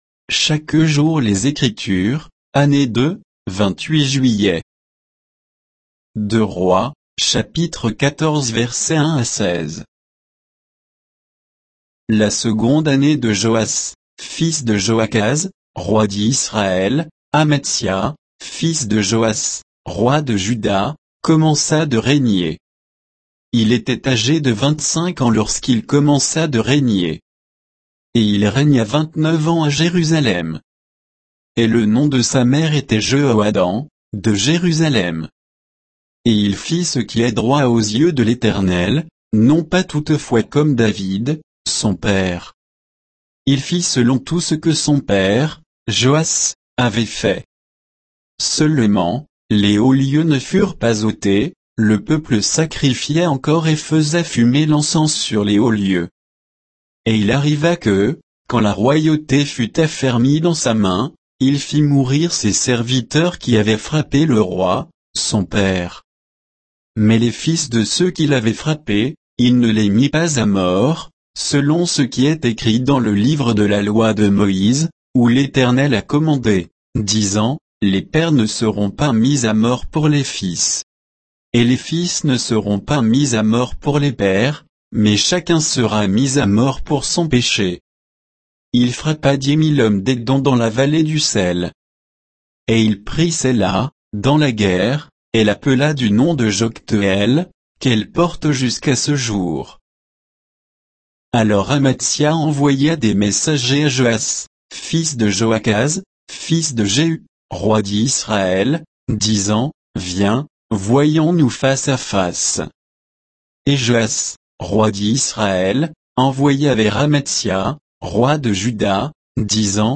Méditation quoditienne de Chaque jour les Écritures sur 2 Rois 14